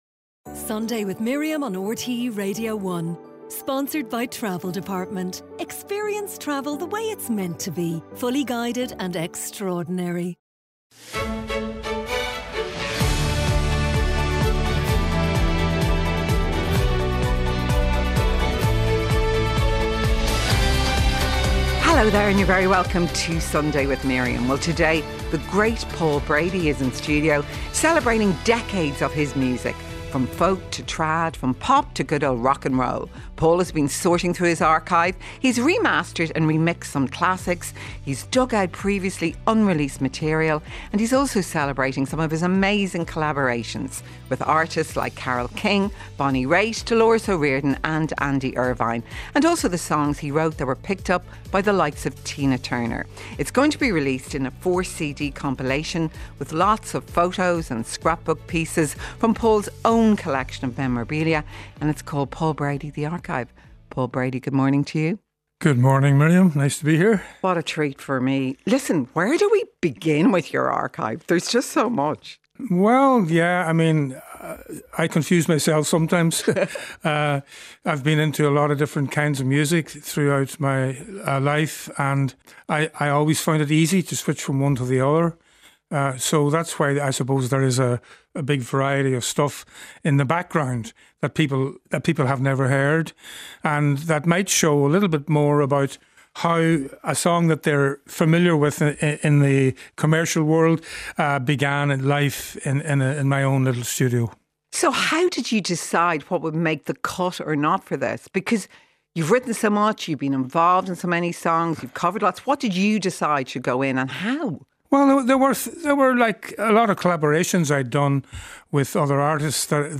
Miriam O'Callaghan presents an all-talking, all-singing, all-human-life-is-here show.